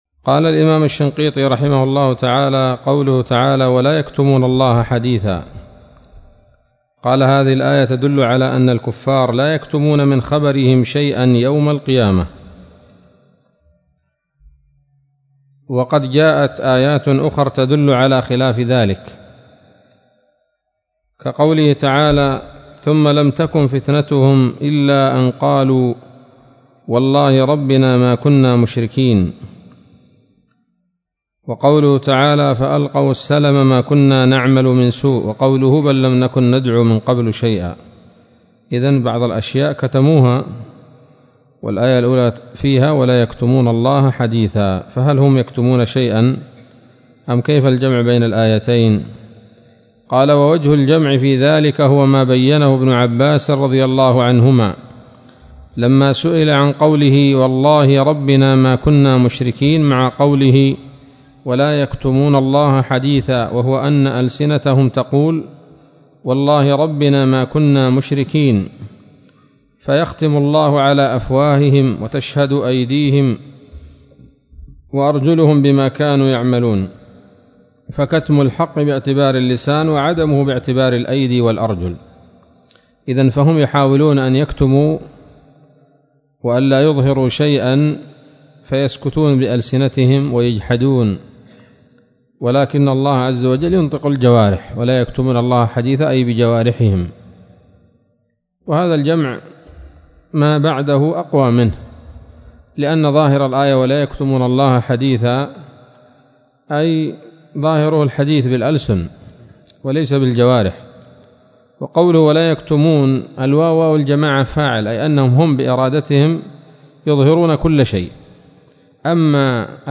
الدرس الثلاثون من دفع إيهام الاضطراب عن آيات الكتاب